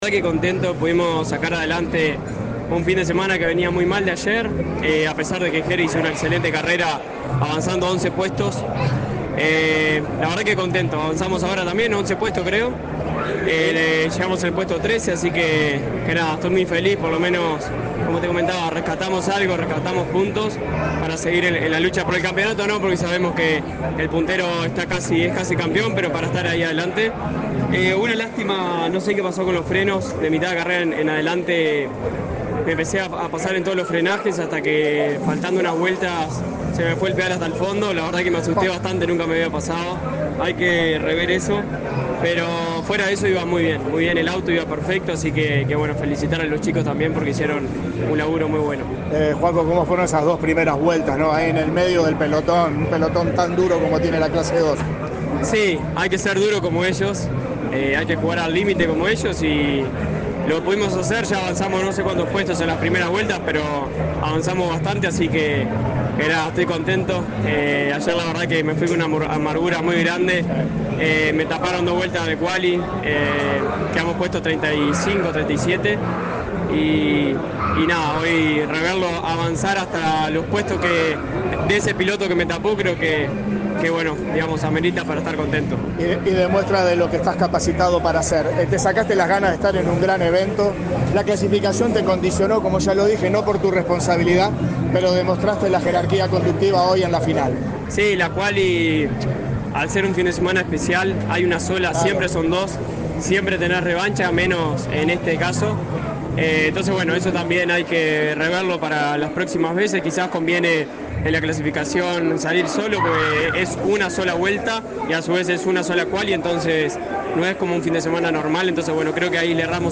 Escuchamos al piloto tras finalizar la competencia…